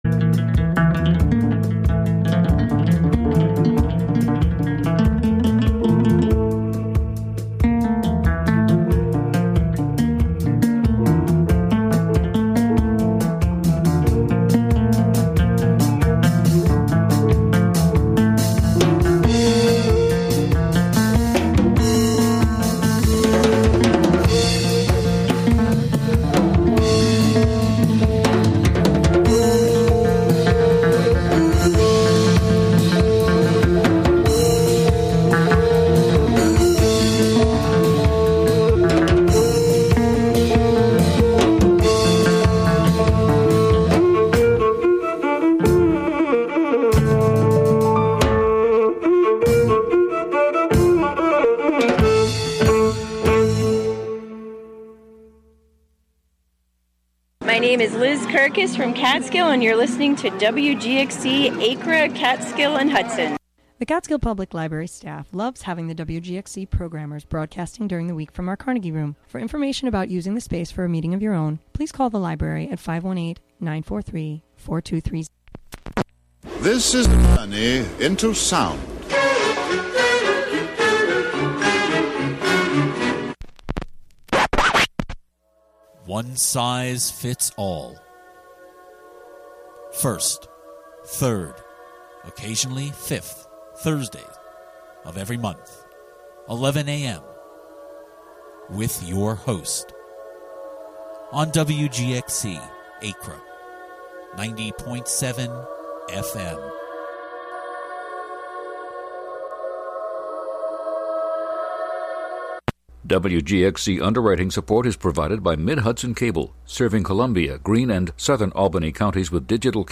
Broadcast live from the Carnegie Room of the Catskill Library.